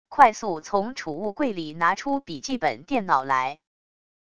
快速从储物柜里拿出笔记本电脑来wav音频